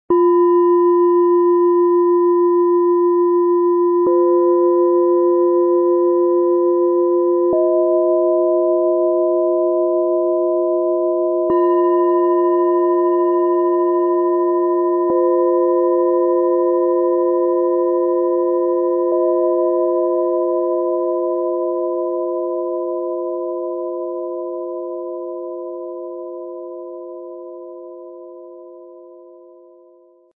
Sanfte Energie für Klarheit, Lebensfreude und inneres Leuchten - Set aus 3 Planetenschalen, Ø 10,3 -15,1 cm, 1,28 kg
Ein heller, feiner Klangraum, der erfrischt, zentriert und deine Gedanken weitet.
Ihr feiner Klang fließt weich durch den Raum - erdend, kühlend, verbindend.
Als kleinste Schale im Set trägt sie hohe, fast schwebende Töne.
Tiefster Ton: Wasser
Bengalen-Schale, Glänzend
Mittlerer Ton: Sonne
Höchster Ton: Wasserstoffgamma